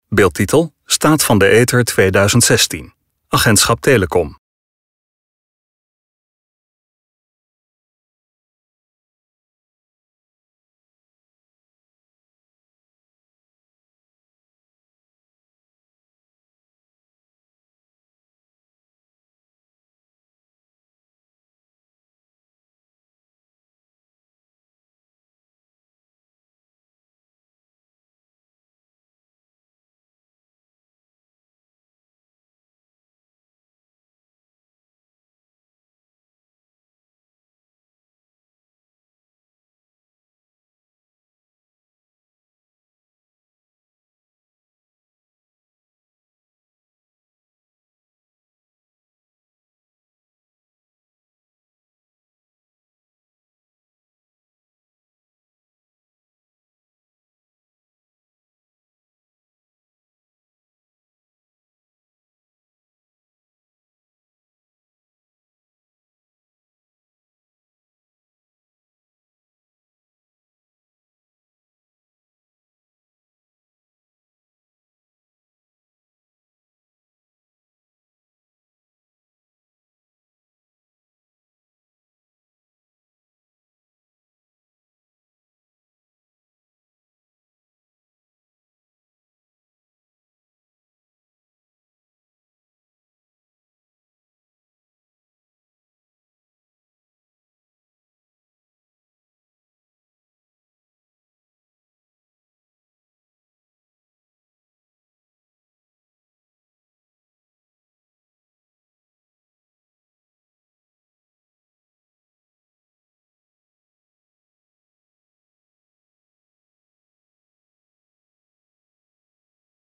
RUSTIGE MUZIEK
DE RUSTIGE MUZIEK SPEELT NOG EVEN VERDER EN EBT WEG